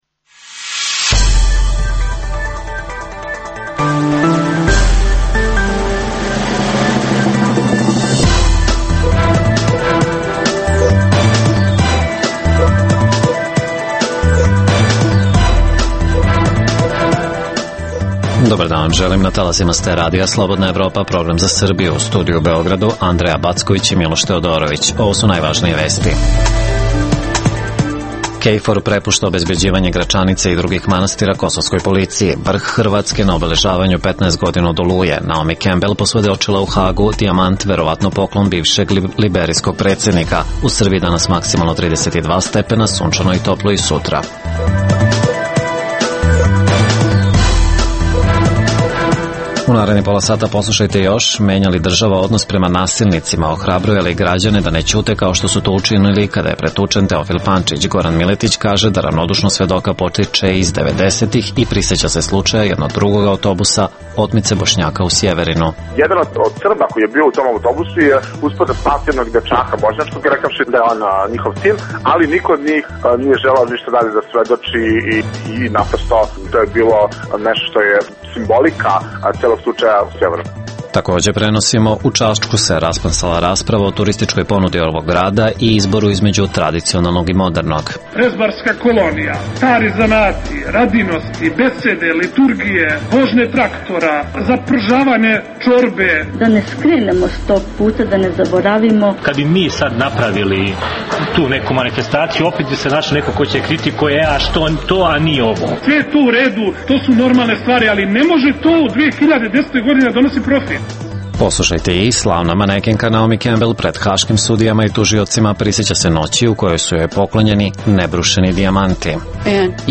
Kfor prepušta Kosovskoj policiji obezbeđivanje Gračanice i drugih pravoslavnih manastira. O tome govore Bajram Redžepi, Bojan Stojanović i Oliver Ivanović.
Poslušajte i delove svedočenja slavne manekenke Naomi Kembel pred Sudom u Hagu